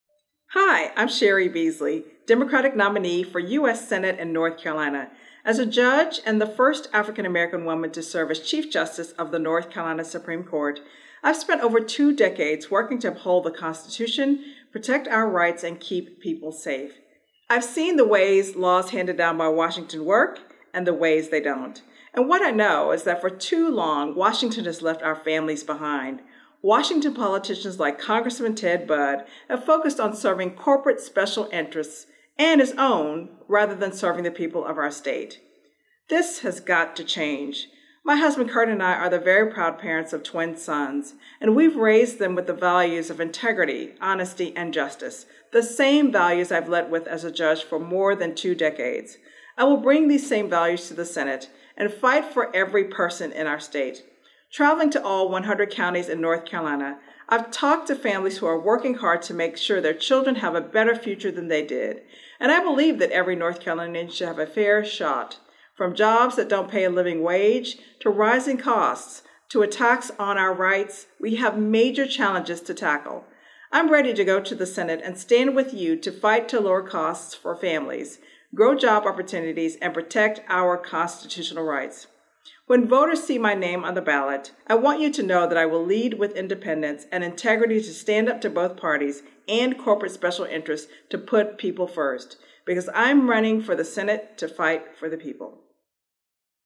Each candidate answered the same set of questions regarding their decision to run for elected office, their backgrounds and what they wish for residents to think of when voting this fall.
Both Beasley and Budd spoke to 97.9 The Hill and Chapelboro to introduce themselves, and their candidacy.